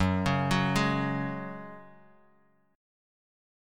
F#sus2 Chord